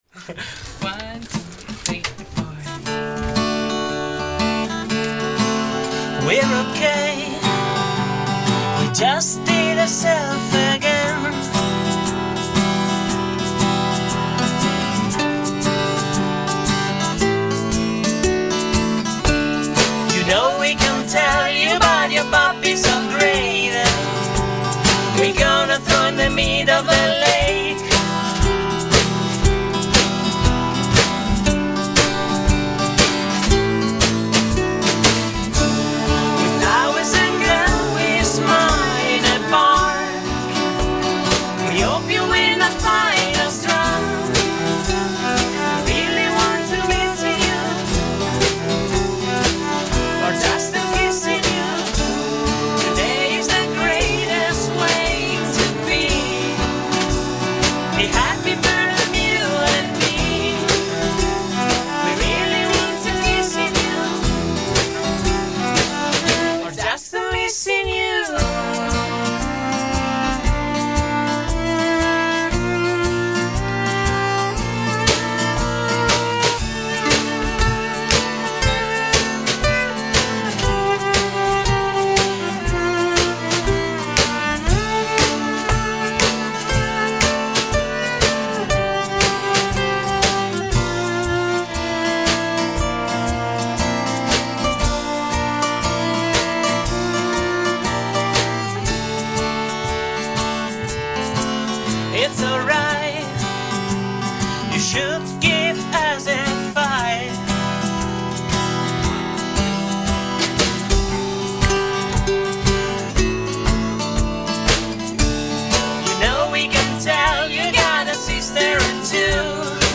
Il miglior indie pop possibile oggi, in Italia.